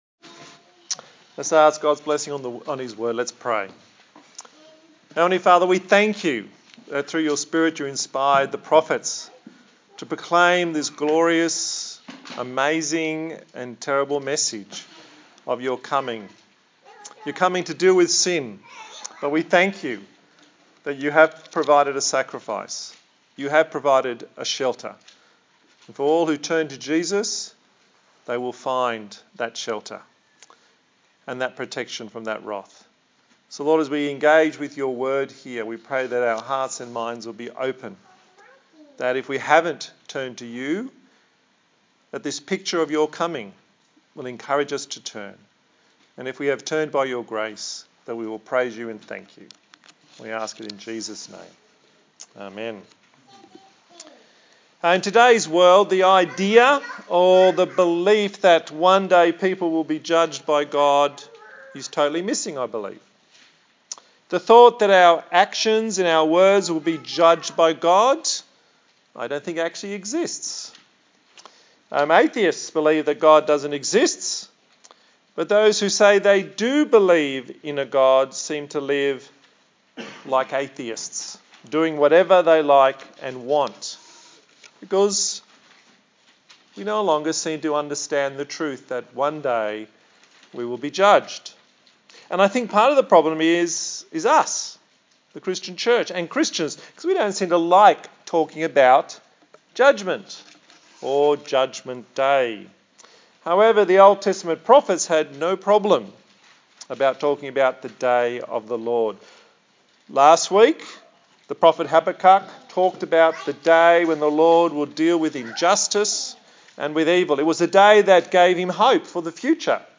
A sermon in the series on the book of Zephaniah